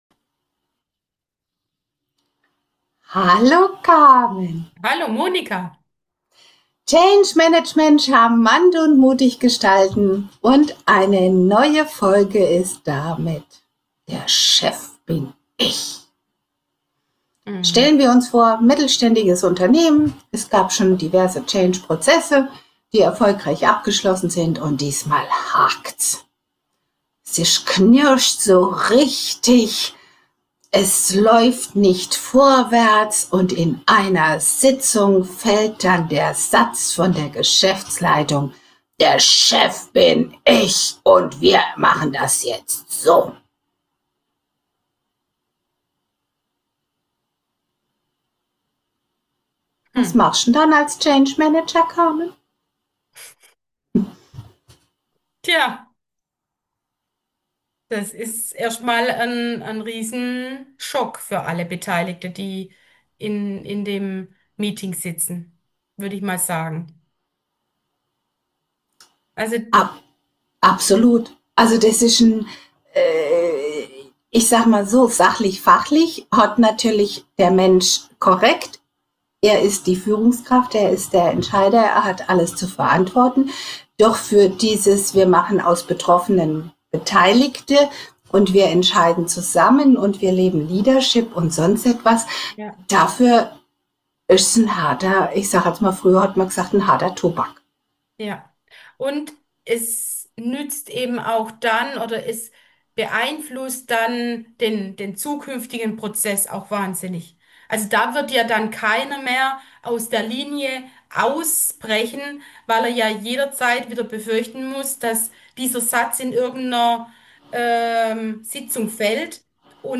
In unserem heutigen Podcastgespräch beleuchten wir eine heikle,